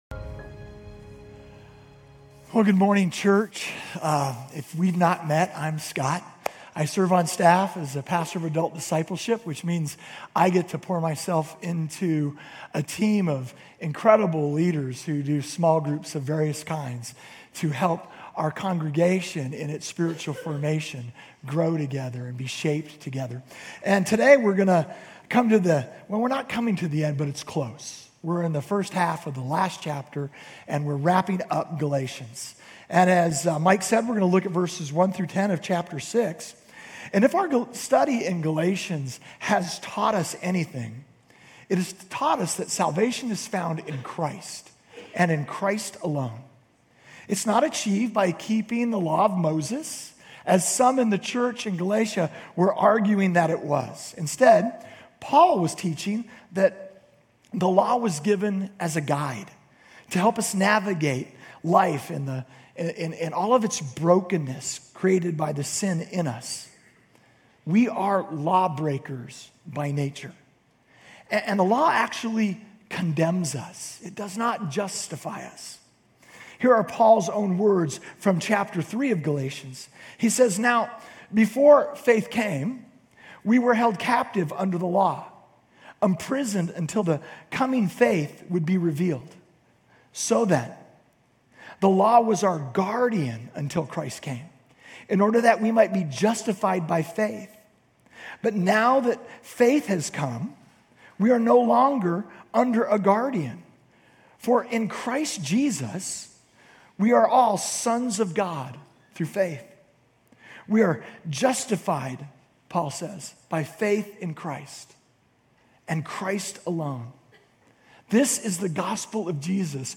Grace Community Church University Blvd Campus Sermons Galatians 6:1-10 Jun 10 2024 | 00:33:31 1x 00:00 / 00:33:31 Subscribe Share RSS Feed Share Link Embed